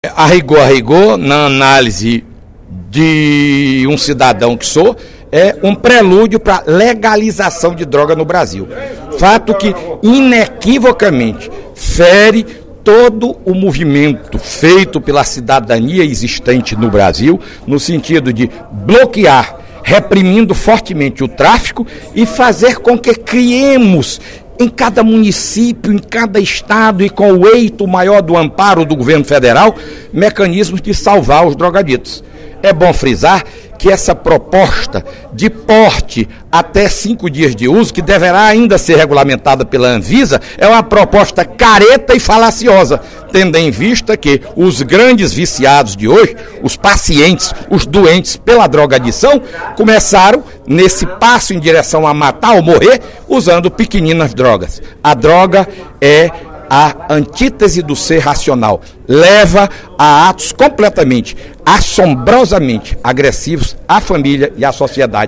O líder do PSDB na Assembleia Legislativa, deputado Fernando Hugo, comentou, na sessão plenária da Casa desta quinta-feira (31/05), proposta que será apresentada à Câmara Federal e Senado por uma comissão de juristas de descriminalização do uso de drogas para efeito pessoal.